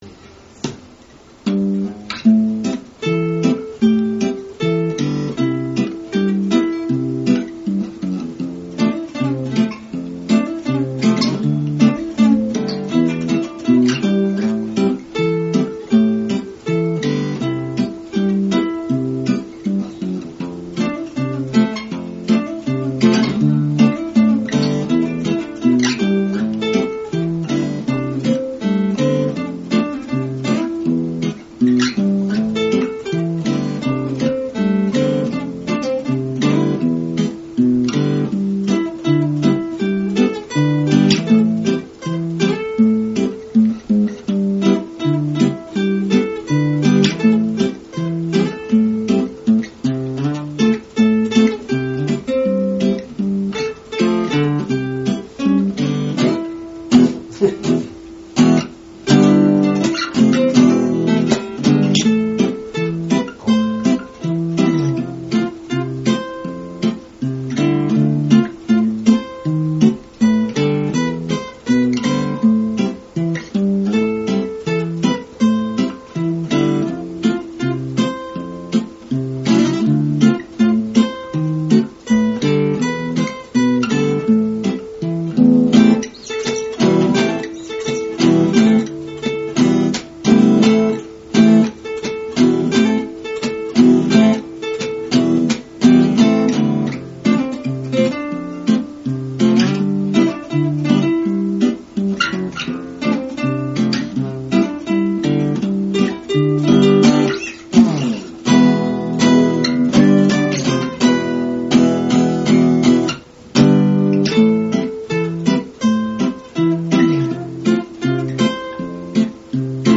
アコースティックギターインスト ゼロゼロヘブン 試聴あり
本日は、カバー曲を中心に練習した。
もう少し精度を上げないと原曲に失礼になるなぁ・・・
リードギター